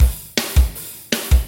OVERDRIVE MUSIC - Boucle de Batteries - Drum Loops - Le meilleur des métronomes
METAL
Normal - Opened hi-hat
Straight / 160 / 1 mes